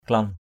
/klɔn/